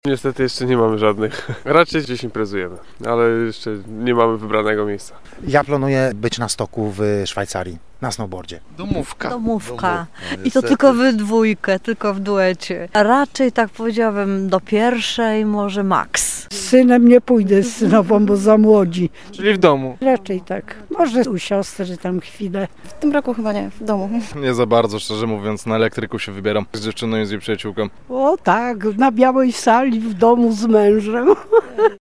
Niektórzy sylwestra spędzą w domowym zaciszu, inną wybierają się na imprezę ze znajomymi. Nasz reporter zapytał mieszkańców Trójmiasta, jak planują powitać nadchodzący rok. Są tacy, którzy ten dzień spędzą za granicą, a część nie ma jeszcze sprecyzowanych planów.